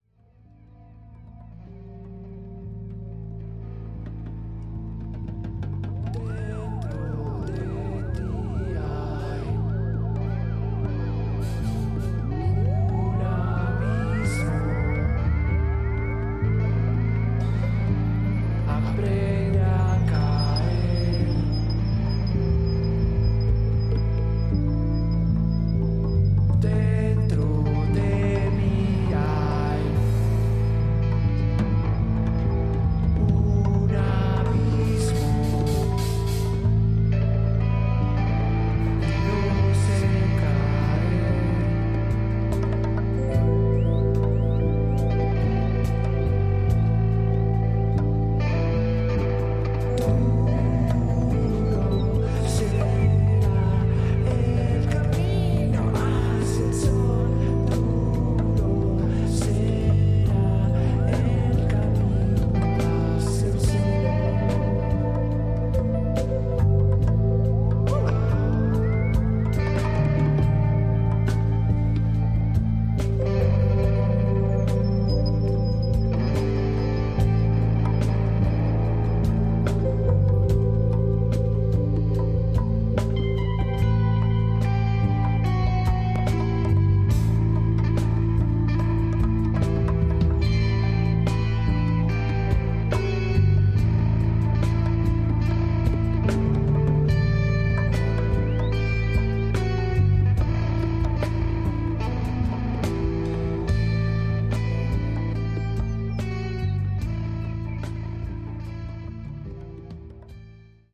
Tags: Rock , Psicodelico , Colombia , Bogotá